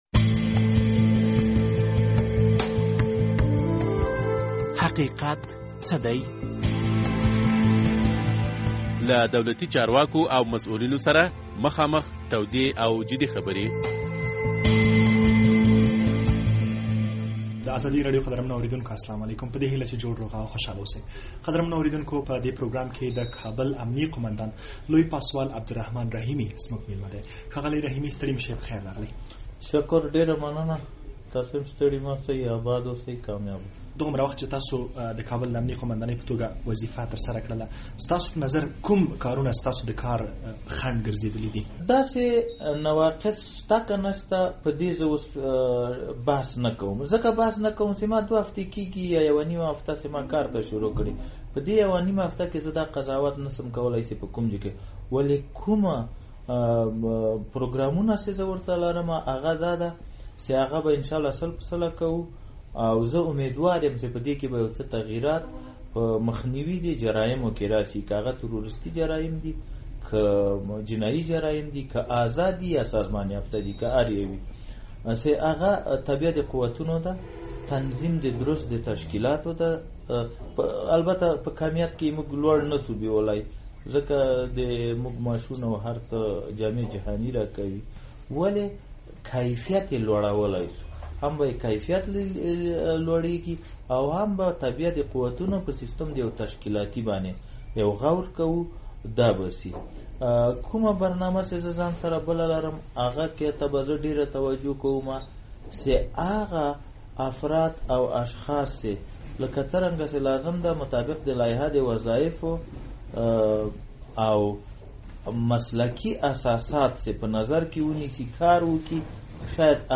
د حقیقت څه دی په دغه پروګرام کې د کابل امنیه قومندان لوی پاسول عبدالرحمن رحیمي میلمه دی. ښاغلی رحیمي وايي، د کابل د امنیت د ښه والي لپاره درې لنډمهالي،منځمهالي او اوږدمهالي پروګرامونه په پام کې لري.